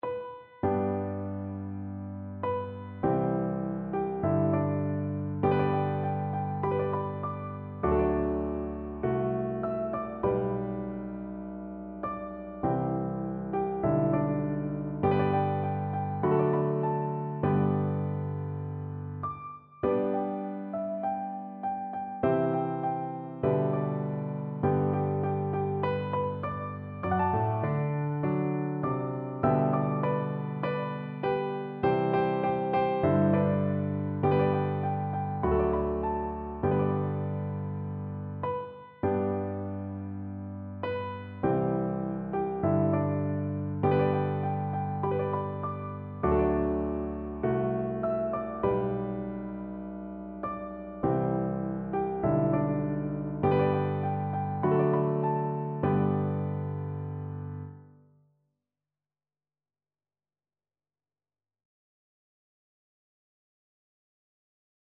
Free Sheet music for Piano Four Hands (Piano Duet)
4/4 (View more 4/4 Music)